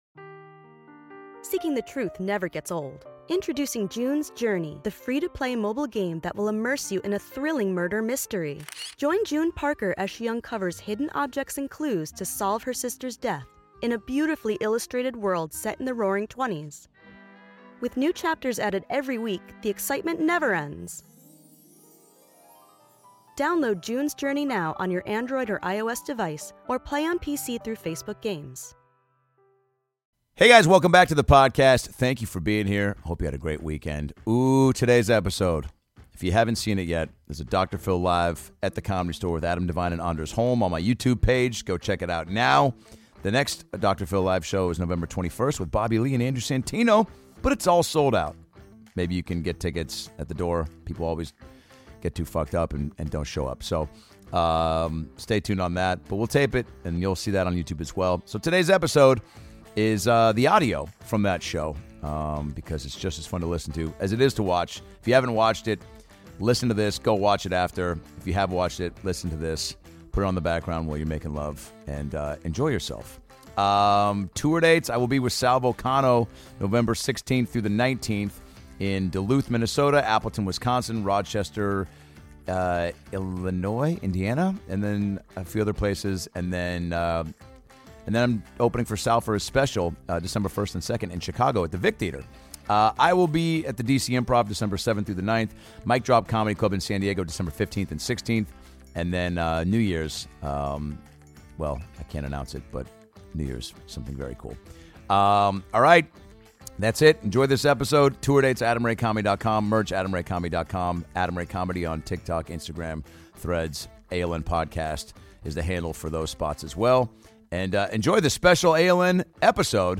#730 - Adam Ray Presents Dr. Phil LIVE! With Adam DeVine & Anders Holm